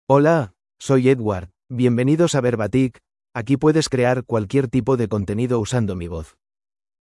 Edward — Male Spanish (Spain) AI Voice | TTS, Voice Cloning & Video | Verbatik AI
Edward is a male AI voice for Spanish (Spain).
Voice sample
Listen to Edward's male Spanish voice.
Edward delivers clear pronunciation with authentic Spain Spanish intonation, making your content sound professionally produced.